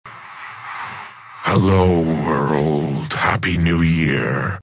Клонирование голоса
После этого можно превращать любой текст в естественную речь, звучащую как оригинал.
Вот что у нас получилось с этим голосом: